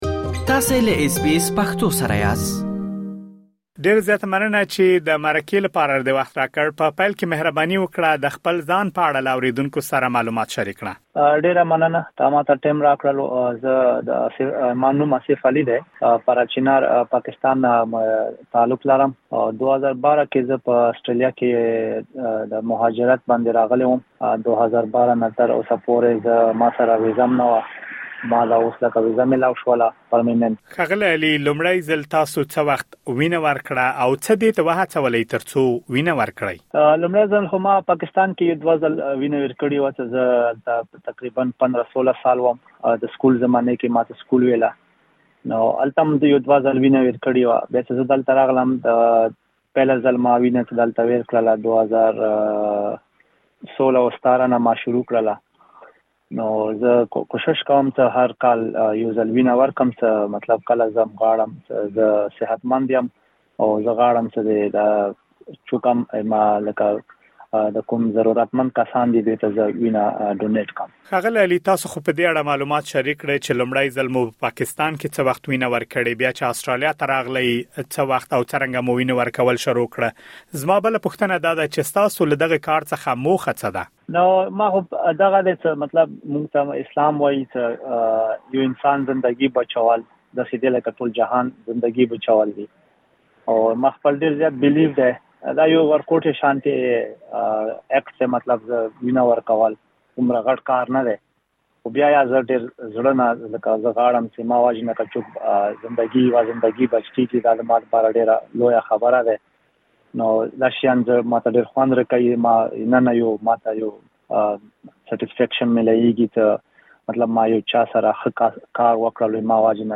لا ډېر معلومات په ترسره شوې مرکې کې اورېدلی شئ.